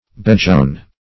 bedgown - definition of bedgown - synonyms, pronunciation, spelling from Free Dictionary Search Result for " bedgown" : The Collaborative International Dictionary of English v.0.48: Bedgown \Bed"gown`\, n. A nightgown.